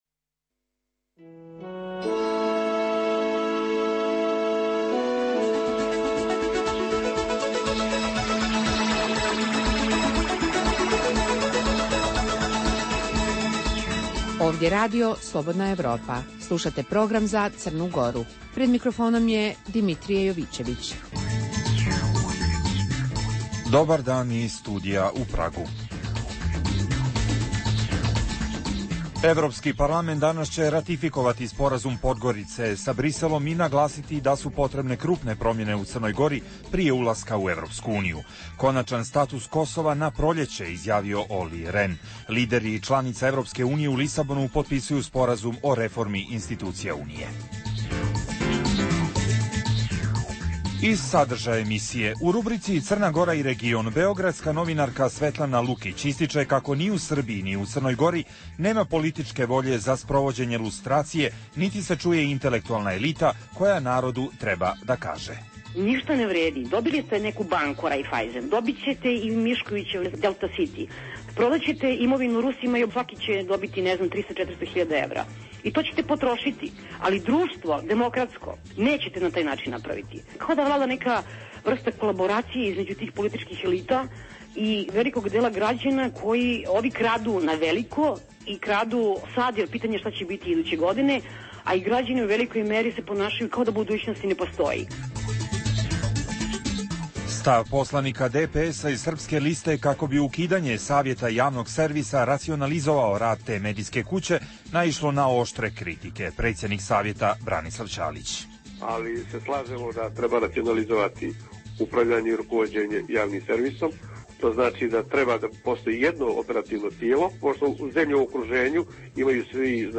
Emisija namijenjena slušaocima u Crnoj Gori. U emisiji se bavimo reakcijama na Rezoluciju Evropskog parlamenta kojom se Crna Gora proziva zbog korpucije i zavisnog pravosudja.